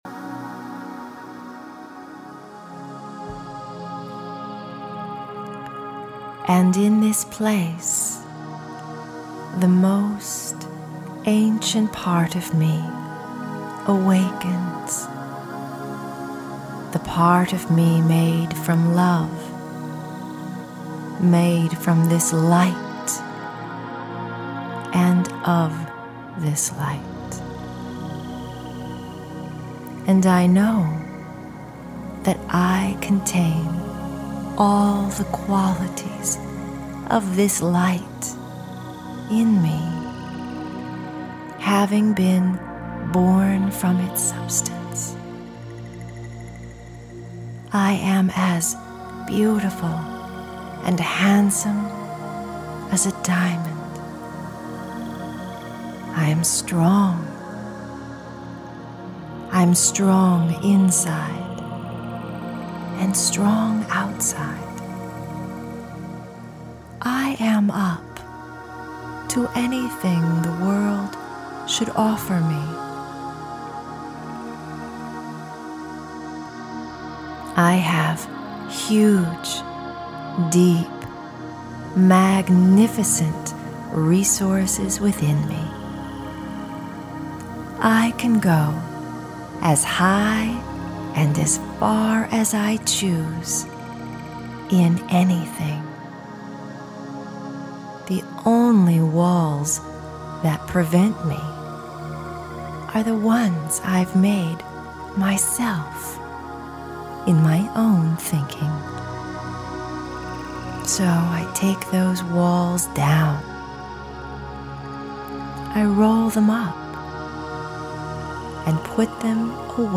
Learn how peaceful sleep meditation can help your dreams become a reality at Flowdreaming.